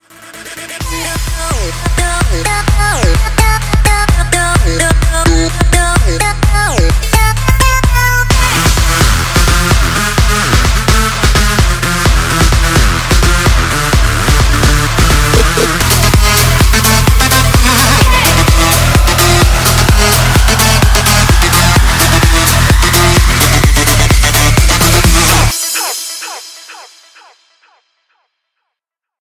trance ringtones